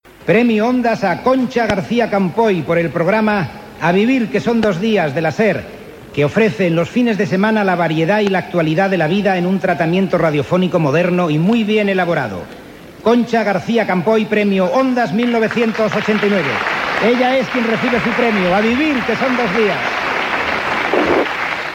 Acte de lliurament dels Premios Ondas 1989.